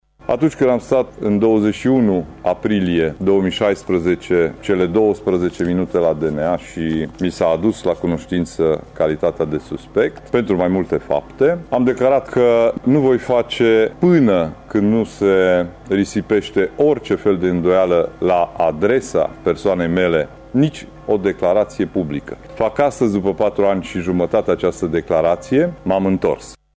Faptele pentru care a fost cercetat în ultimii 4 ani și jumătate avocatul tîrgumureșean Ciprian Dobre, nu au existat, iar dosarul a fost clasat, a anunțat acesta luni, în cadrul unei conferințe de presă.